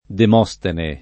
[ dem 0S tene ]